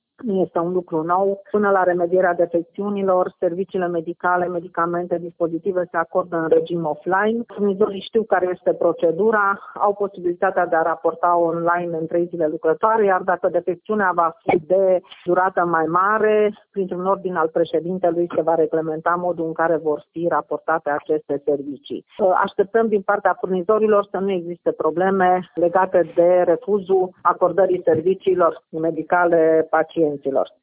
Președintele Casei Județene de Asigurări de Sănătate Mureș, Rodica Biro, a precizat că, deocamdată, nu se cunoaște momentul în care sistemul va redeveni funcțional: